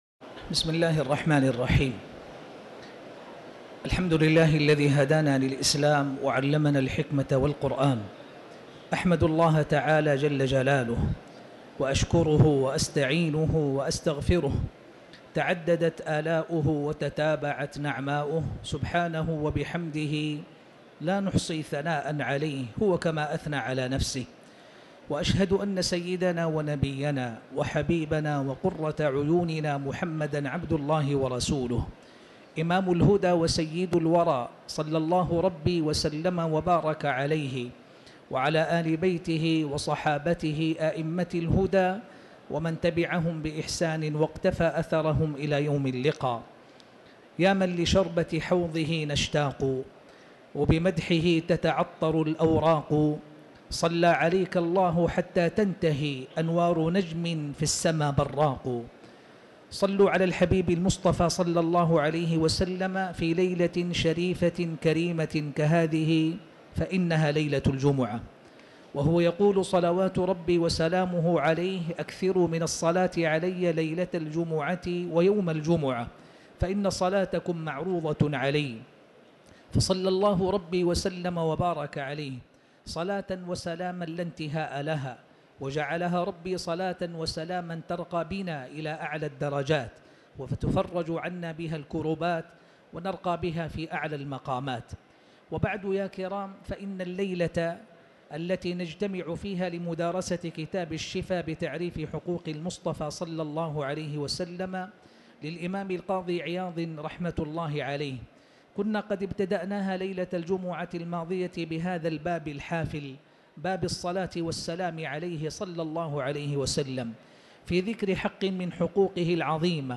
تاريخ النشر ٧ رجب ١٤٤٠ هـ المكان: المسجد الحرام الشيخ